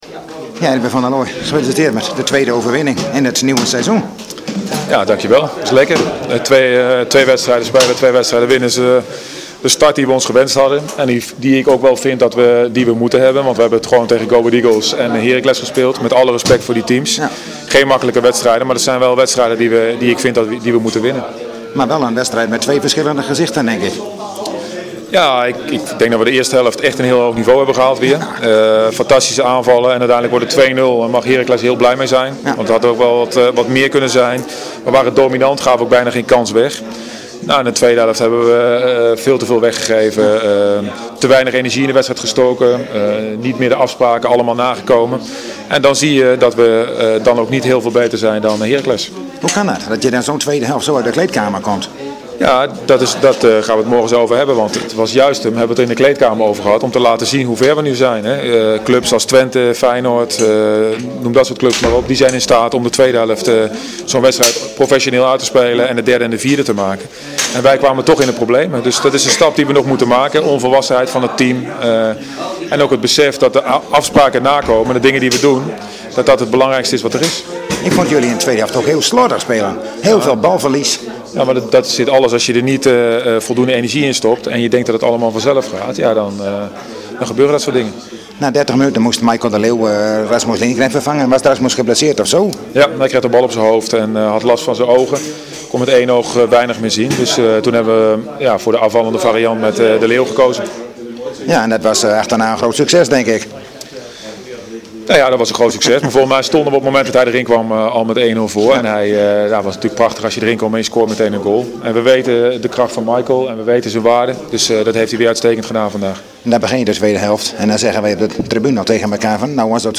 Trainer Erwin van de Looi in gesprek